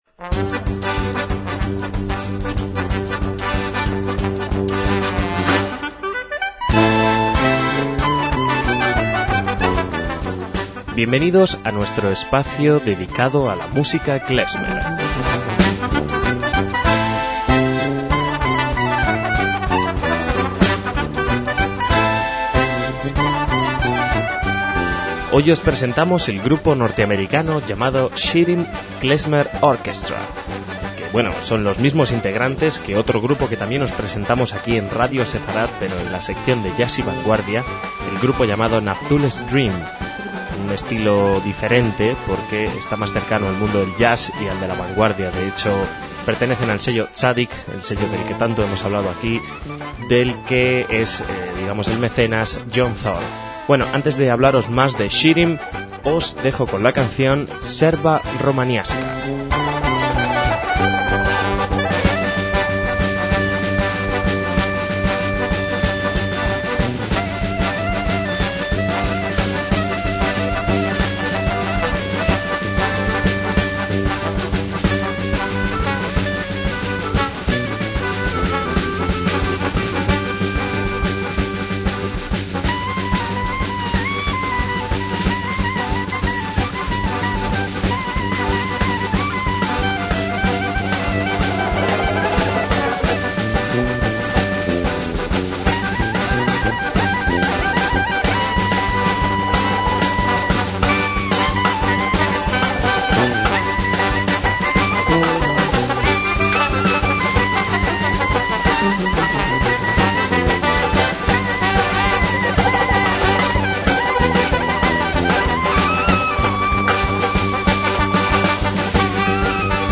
MÚSICA KLEZMER
clarinete